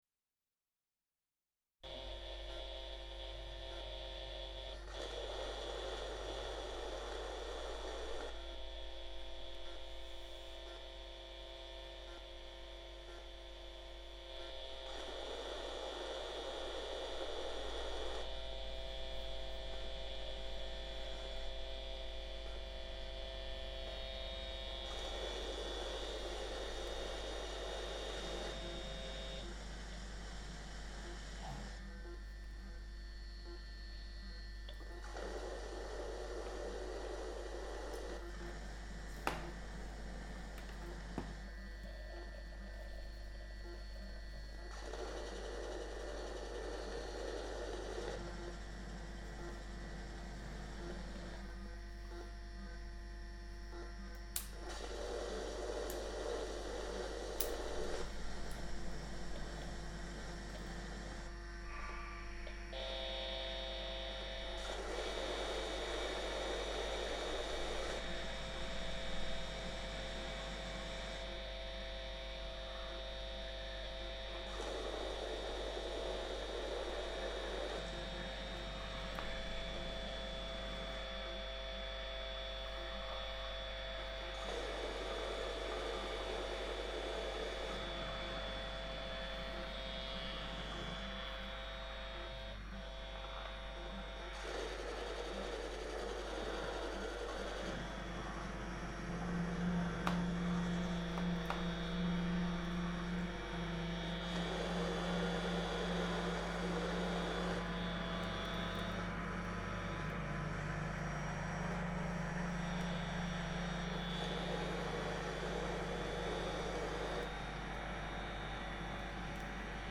Maerz Improvisation am Sonntag, 13. Maerz 2005. Mit Radio, Minidisc Recorder, Powerbook. Max/MSP delay pitch shift oscillator. Olympus Pearlcoder S907 Microcassette Recorder, Lautsprecher, Harmonium.